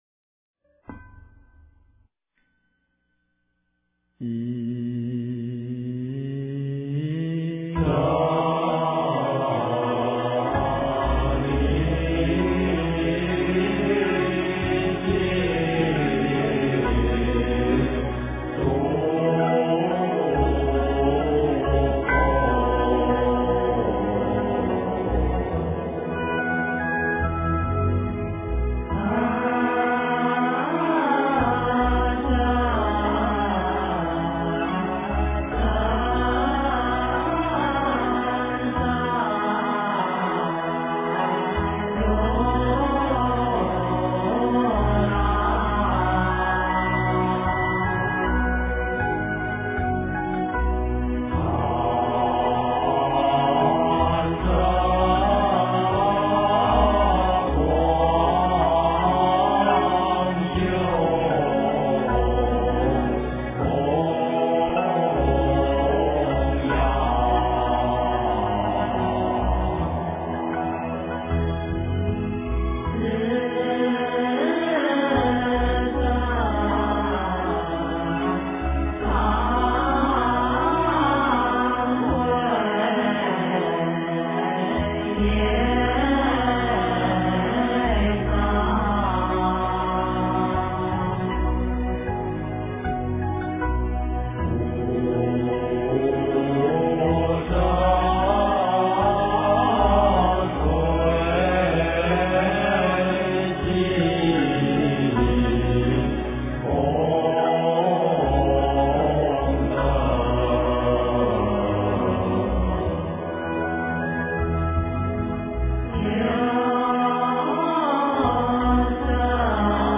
普贤十大愿-唱诵--佛音 经忏 普贤十大愿-唱诵--佛音 点我： 标签: 佛音 经忏 佛教音乐 返回列表 上一篇： 普佛(代晚课)(上)--僧团 下一篇： 三时系念--悟道法师 相关文章 佛堂--未知 佛堂--未知...